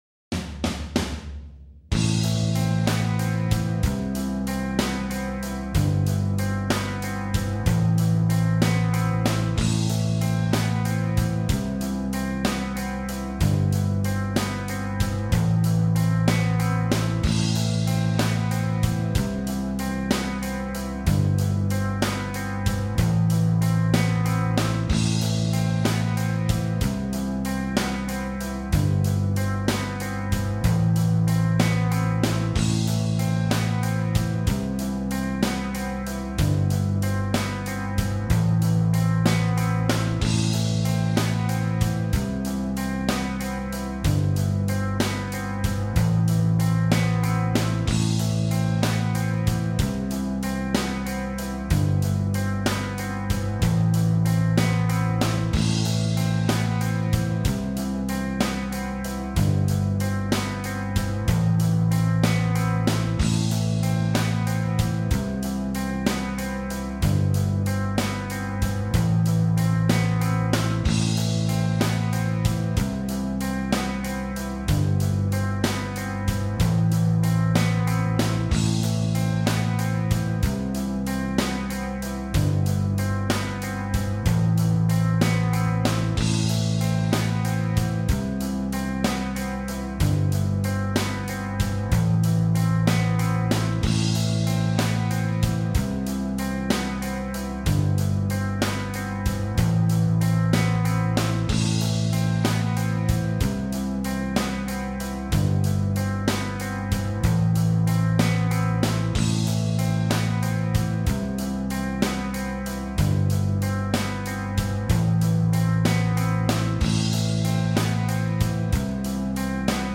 una balada con un solo muy bluesero
backing track para que te lo descargues, u yeiah!!